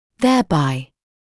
[ˌðeə’baɪ][ˌзэа’бай]таким образом; посредством этого; в связи с этим